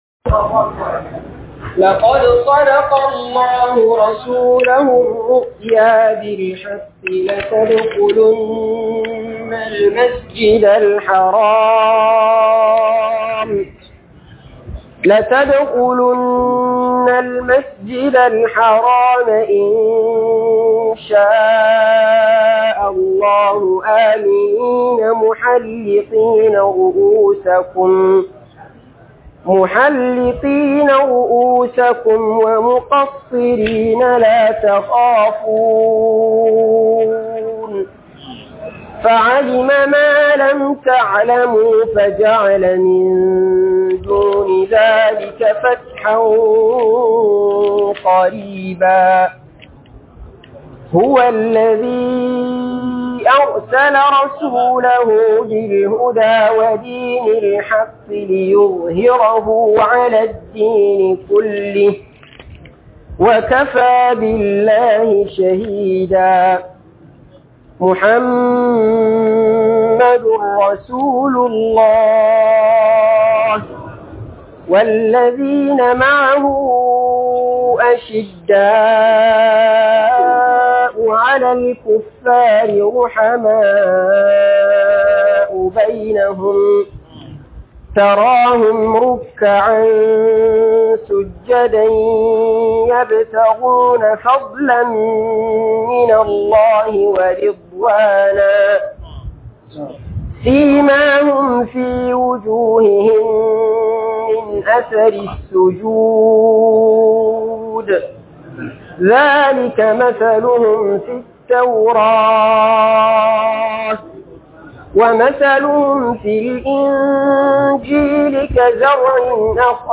FALALAR SAHABBAI - MUHADARA